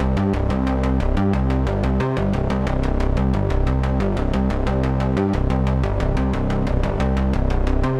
Index of /musicradar/dystopian-drone-samples/Droney Arps/90bpm
DD_DroneyArp1_90-C.wav